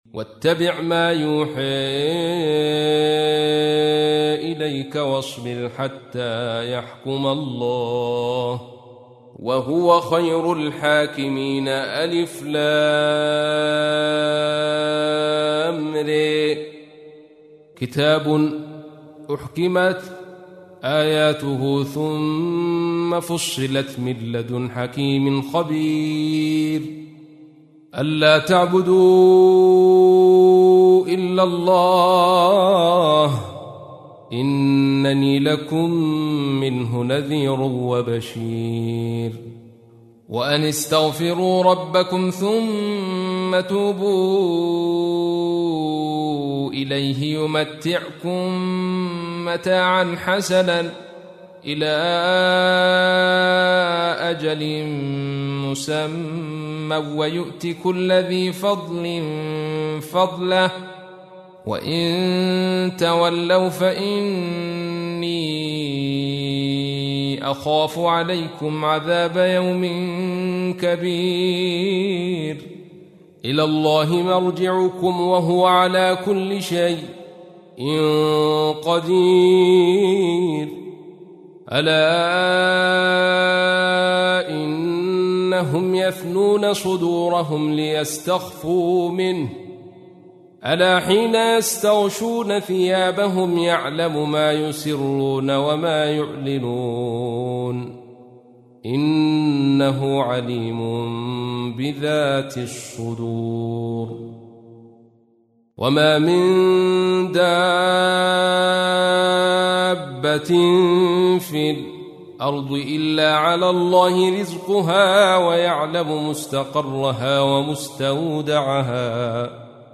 تحميل : 11. سورة هود / القارئ عبد الرشيد صوفي / القرآن الكريم / موقع يا حسين